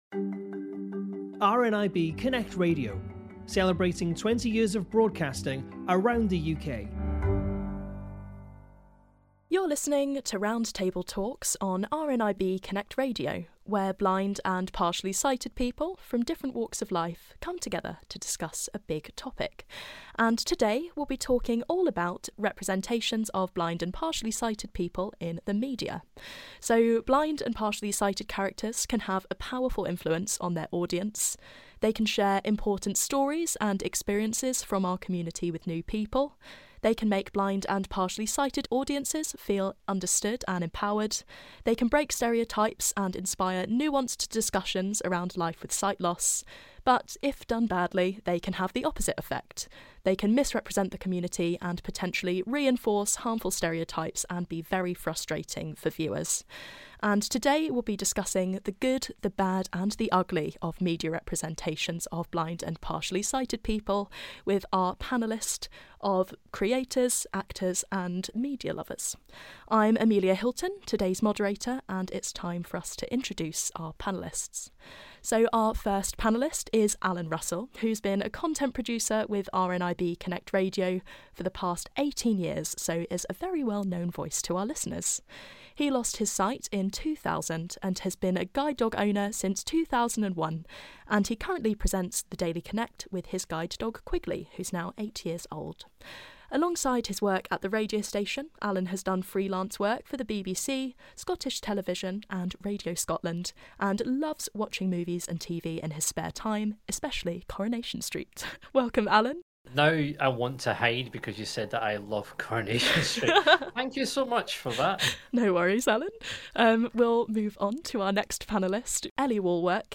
Media Representations of Blind and Partially Sighted People - 20th Anniversary Roundtable